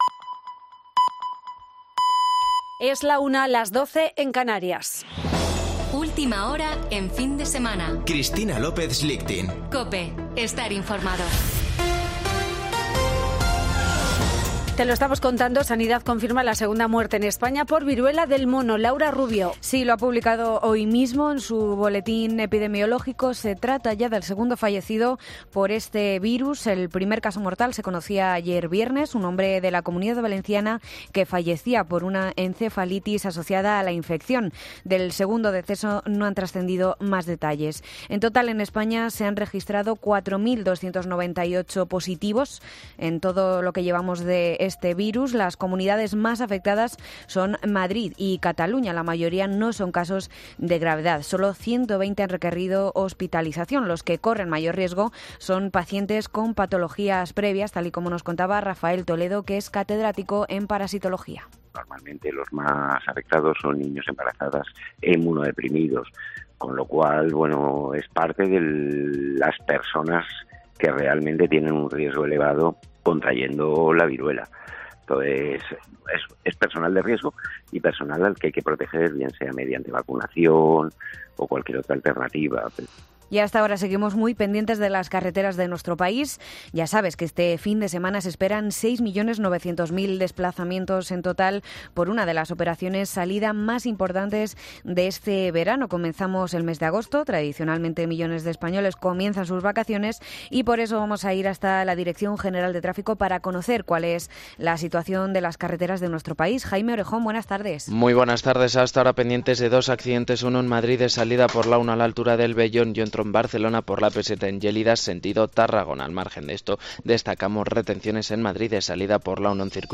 Boletín de noticias de COPE del 30 de julio de 2022 a las 13:00 horas